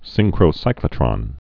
(sĭngkrō-sīklə-trŏn, sĭn-)